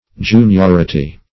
juniority - definition of juniority - synonyms, pronunciation, spelling from Free Dictionary
Juniority \Jun*ior"i*ty\, n.